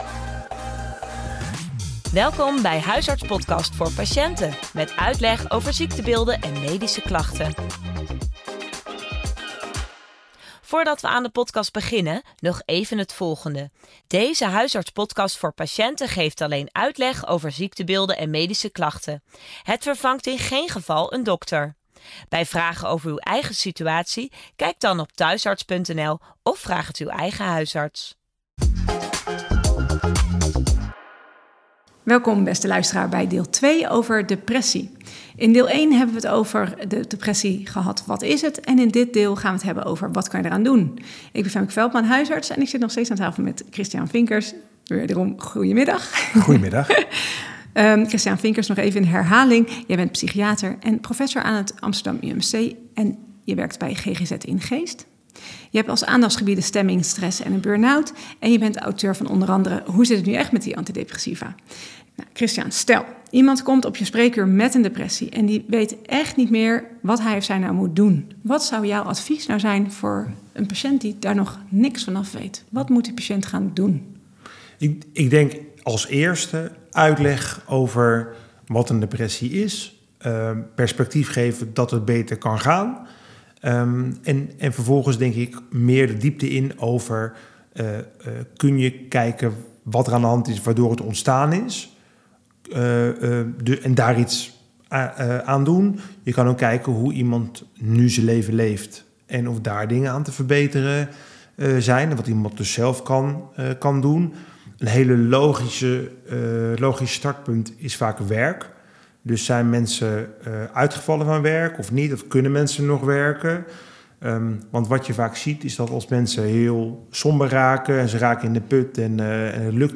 Een interview